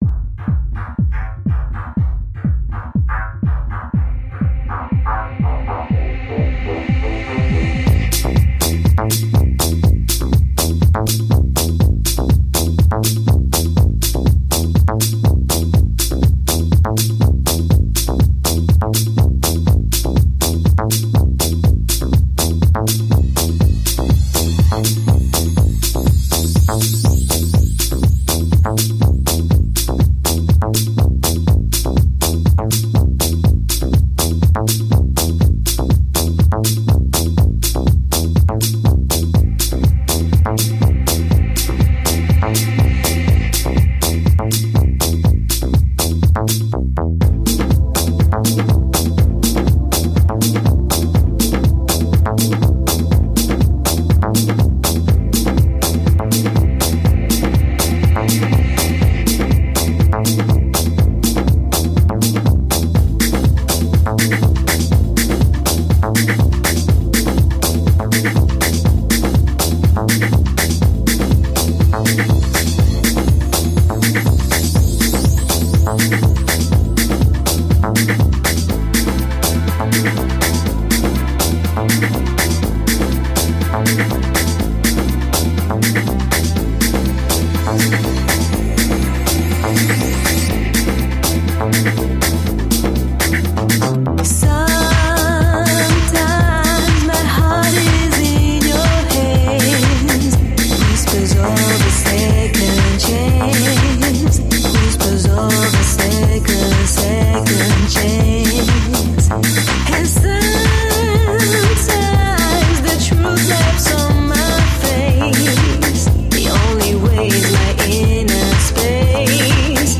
Melancholy, loneliness, desire.